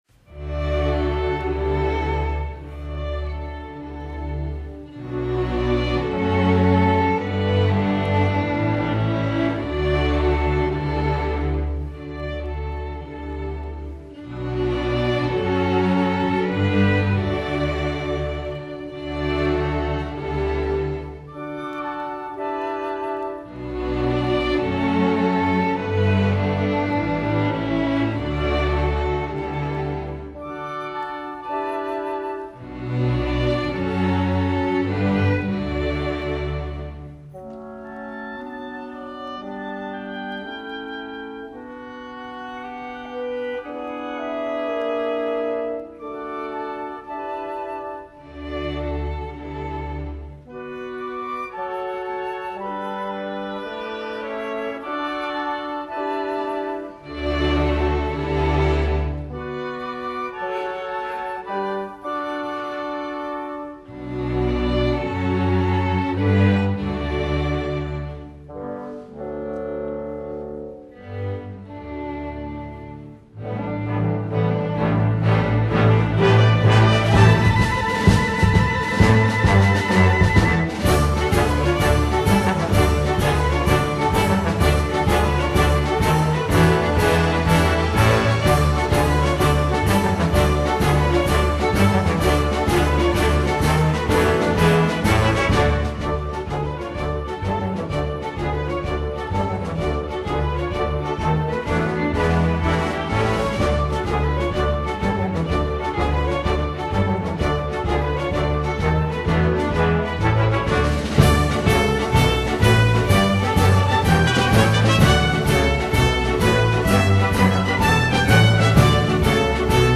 Theme: Americana, 4th of July
Category: GRADE 3, Pops, Special Events, Youth Orchestra
Ensemble: Full Orchestra, Chamber Orchestra